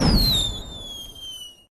firework_whistle_01.ogg